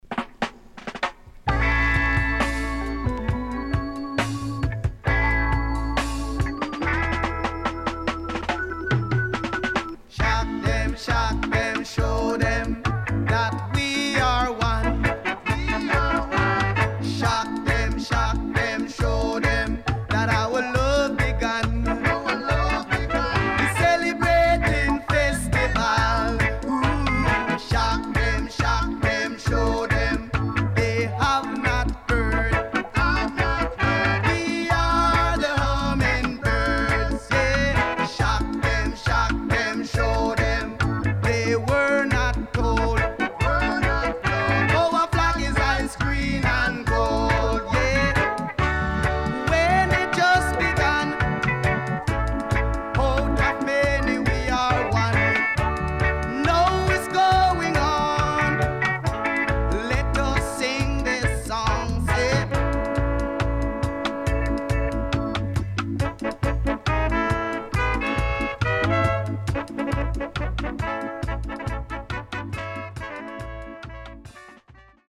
HOME > DISCO45 [VINTAGE]  >  KILLER & DEEP